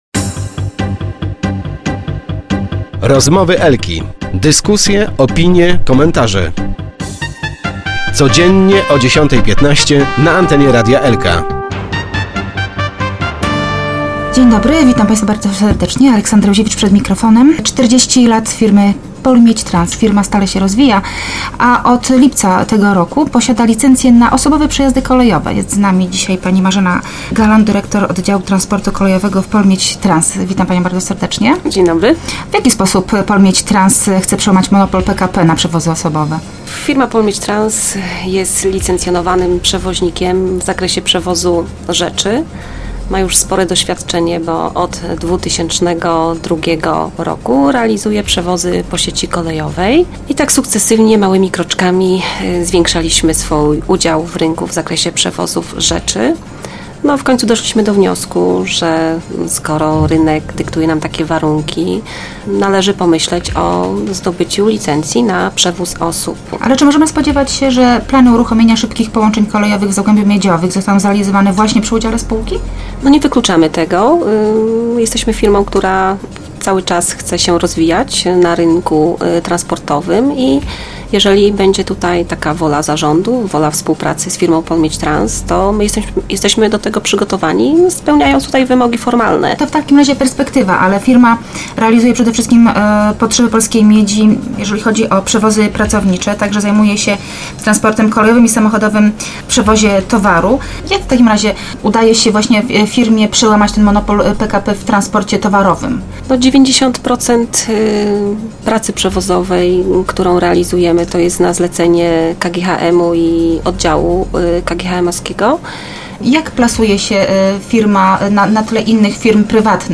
Start arrow Rozmowy Elki arrow Pol Miedź Trans chce się rozwijać